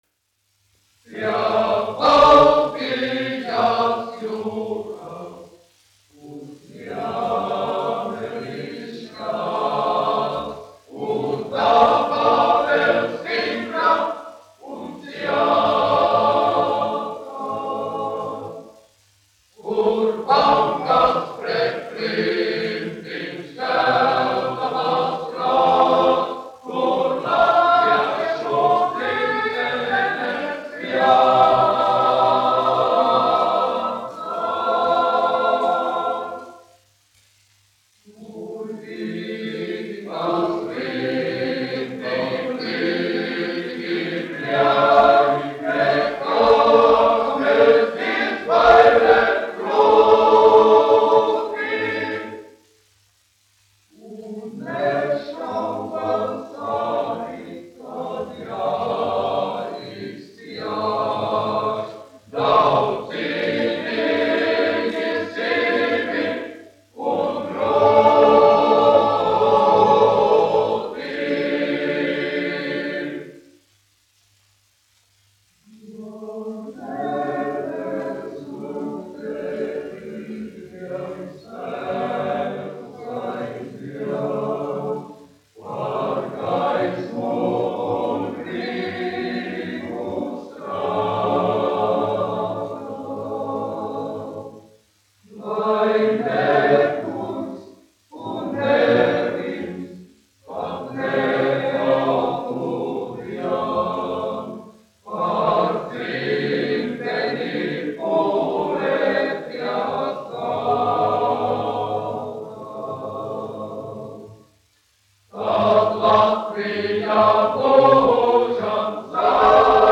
Dziedonis (koris : Rīga, Latvija), izpildītājs
Sergejs Duks, 1902-1973, diriģents
1 skpl. : analogs, 78 apgr/min, mono ; 25 cm
Kori (vīru)
Skaņuplate